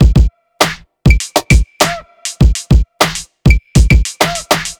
TI100BEAT1-L.wav